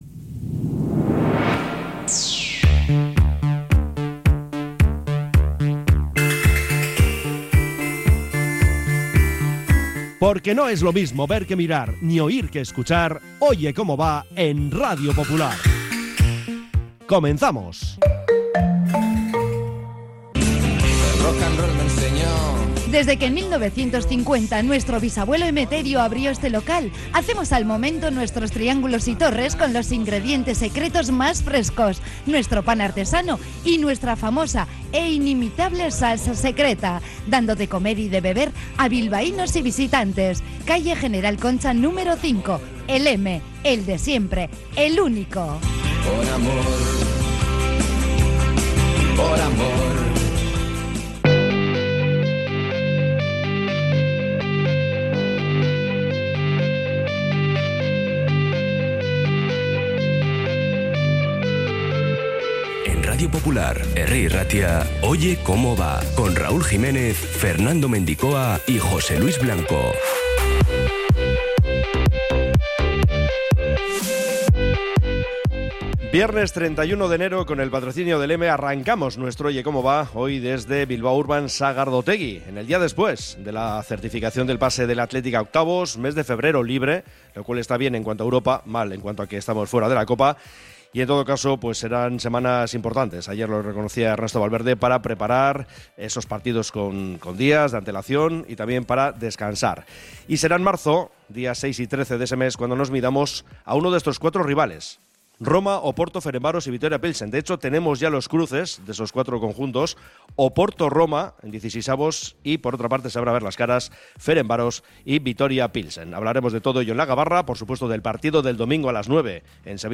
Tramo informativo de 13.30 a 14h